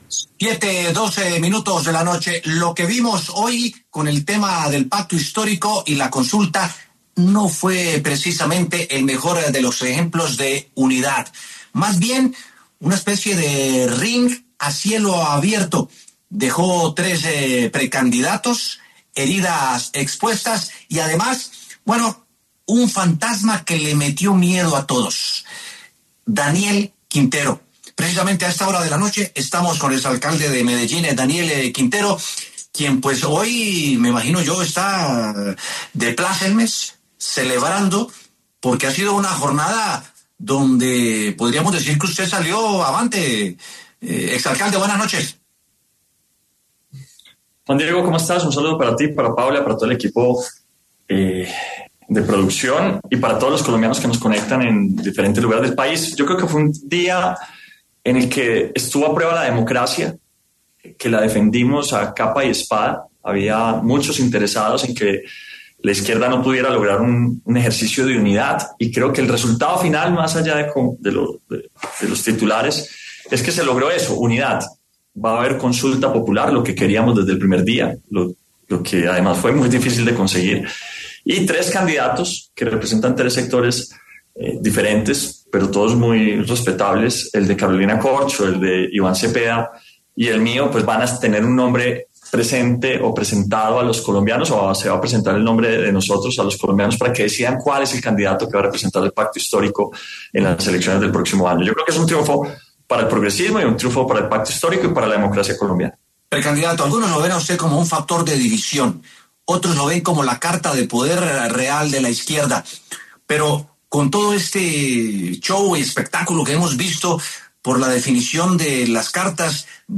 El precandidato presidencial Daniel Quintero habló en los micrófonos de W Sin Carreta y se refirió a la decisión que anunció Gustavo Bolívar, en la que dijo que apoyará la precandidatura presidencial del senador Iván Cepeda, luego de que decidiera no inscribirse a la consulta del Pacto Histórico.